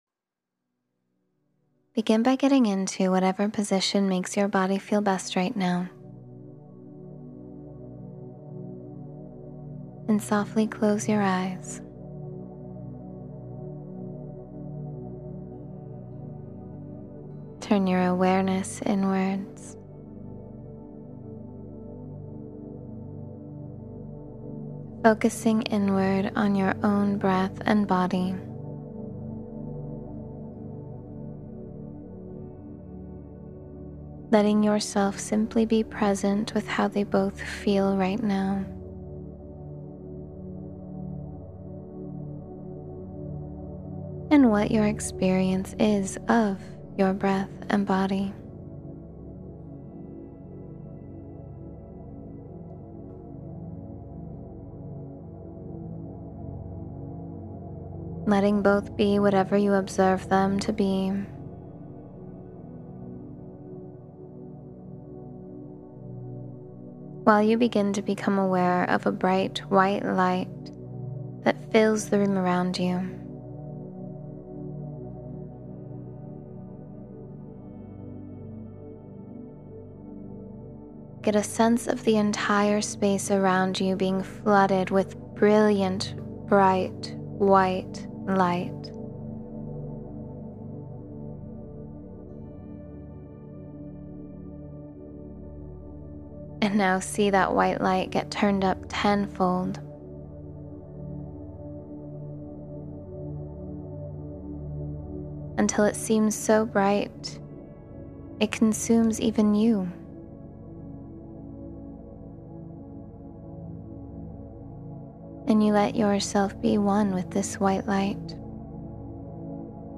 Merge with the Light Within — Meditation for Spiritual Awakening